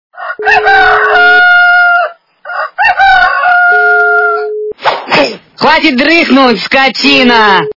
» Звуки » Смешные » Крик сельскои женщины - Хватит дрыхнуть скотина
При прослушивании Крик сельскои женщины - Хватит дрыхнуть скотина качество понижено и присутствуют гудки.